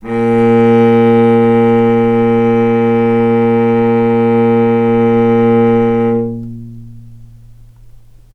healing-soundscapes/Sound Banks/HSS_OP_Pack/Strings/cello/ord/vc-A#2-mf.AIF at cc6ab30615e60d4e43e538d957f445ea33b7fdfc
vc-A#2-mf.AIF